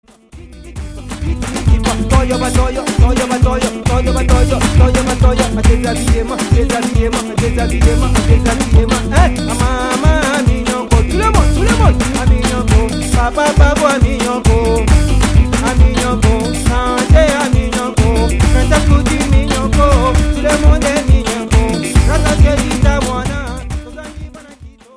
Ghettosounds aus Kaya
Alles was wir brauchten war ein kleines Studio.
...und mein PC als Aufnahmegerät.
traditionellem Musikstück aus der Elfenbeinküste